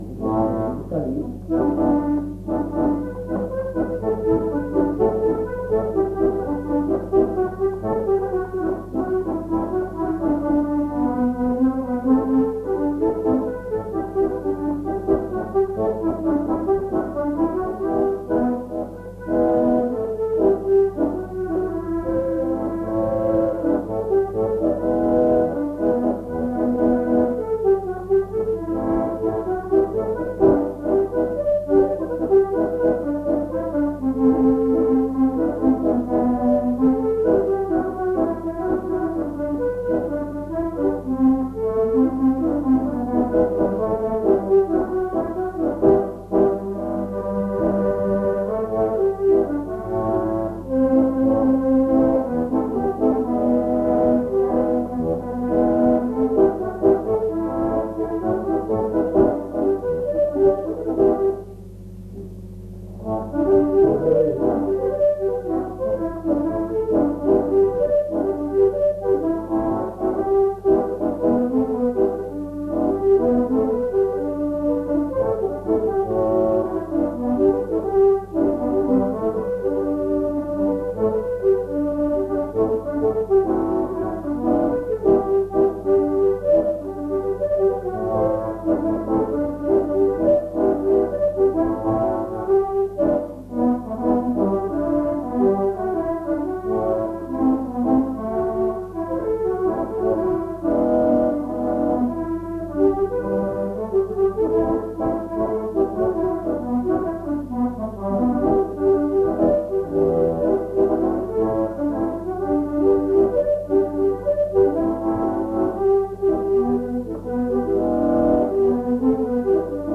Aire culturelle : Marmandais gascon
Genre : morceau instrumental
Instrument de musique : accordéon diatonique
Danse : quadrille
Notes consultables : Interprète plusieurs figures. Le collecteur tente de rejouer le morceau.